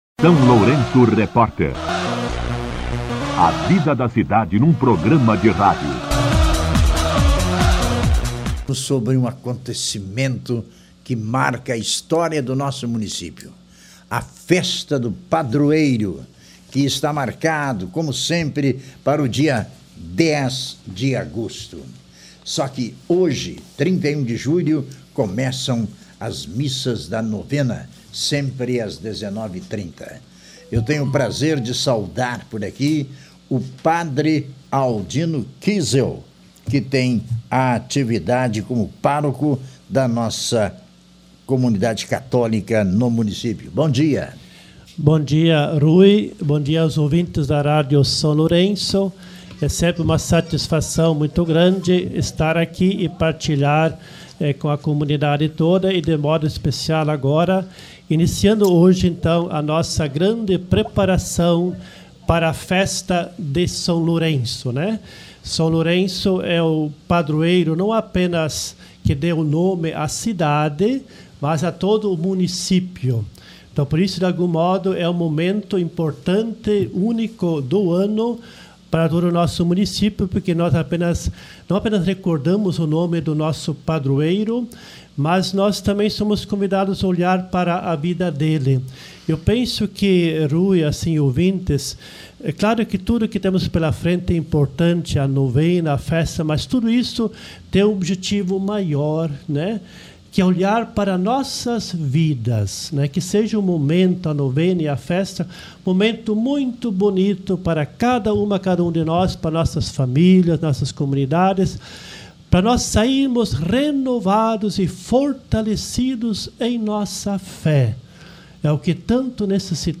Entrevista com a organização da festa